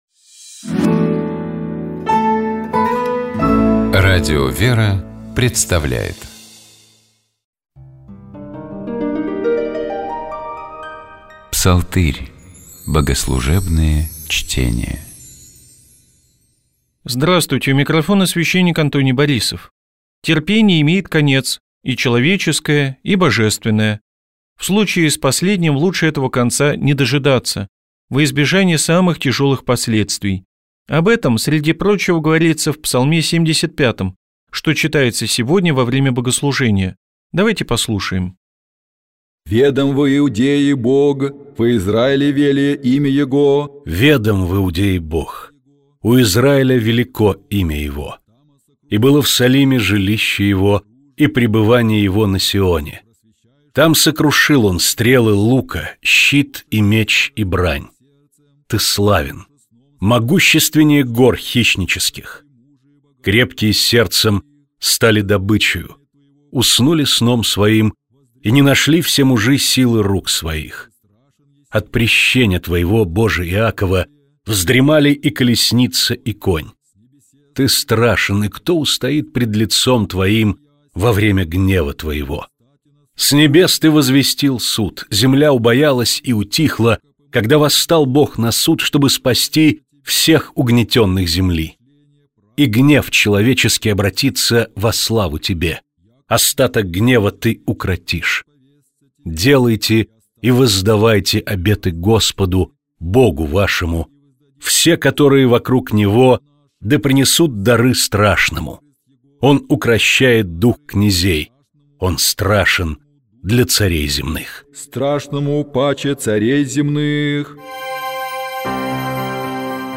Псалом 115. Богослужебные чтения